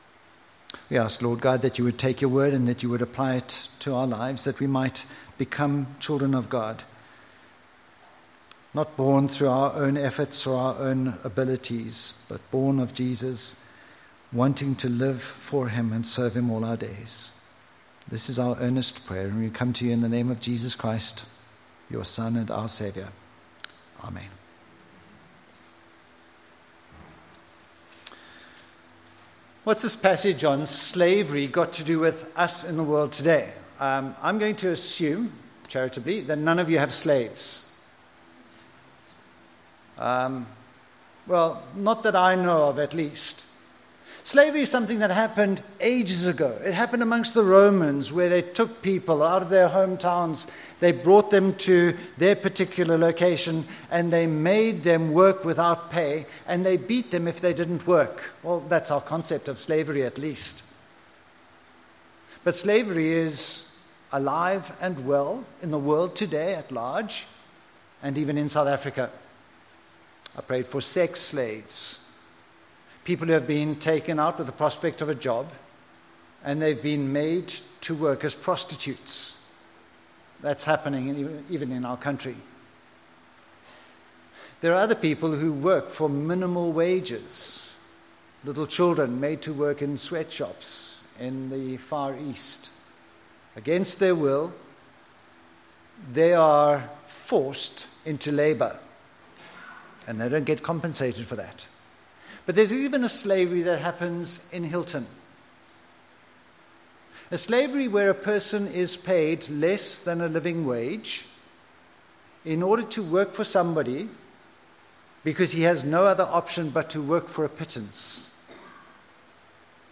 Romans 12:1-8 Service Type: Sunday Morning For their sake?